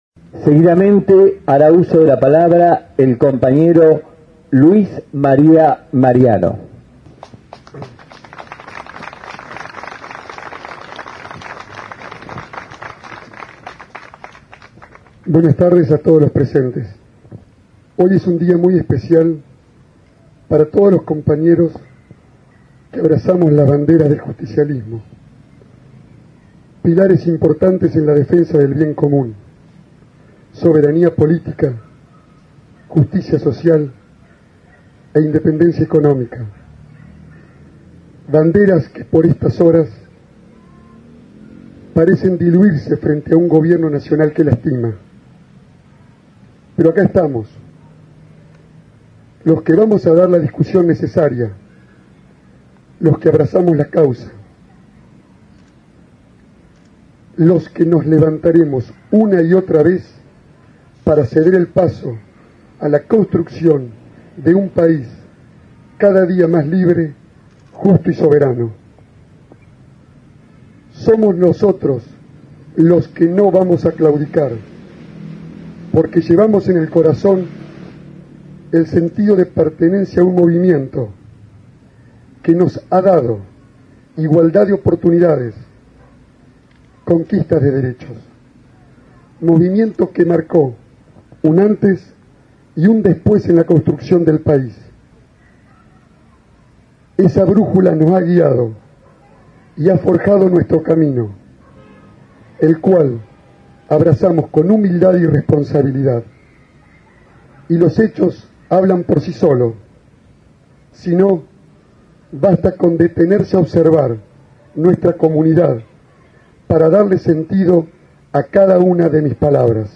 Acto del Día de la Lealtad en Bolívar
Palabras Alusivas del Concejal Luis María Mariano